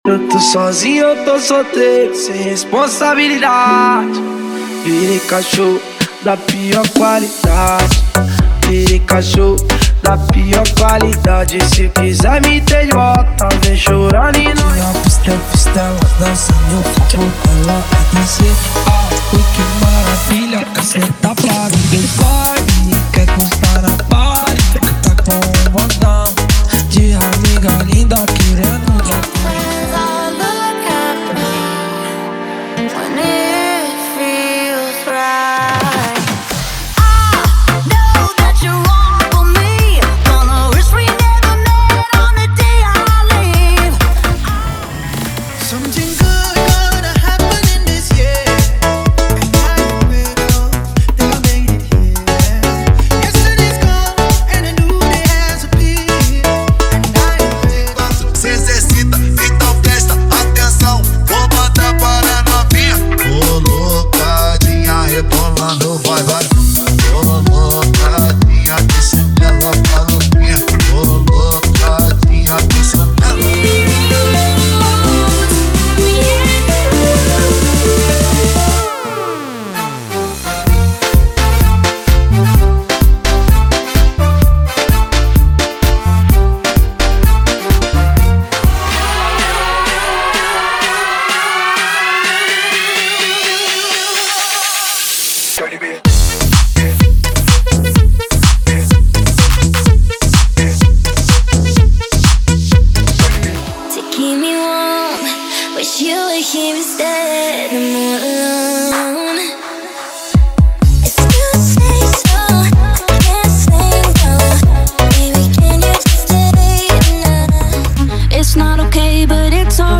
• Dance Comercial = 100 Músicas
• Sem Vinhetas